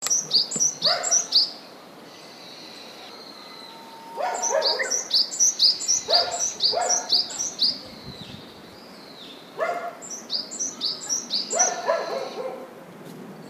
sizyuukara2.mp3